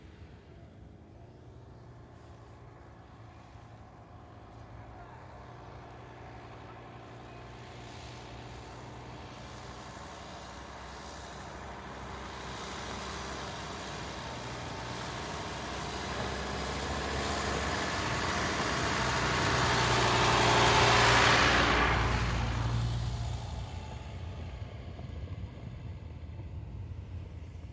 皮卡 " 皮卡 福特62速运
描述：1962年福特皮卡车在泥土和碎石路上加速。
标签： 卡车 皮卡 速度快 驱动器 灰尘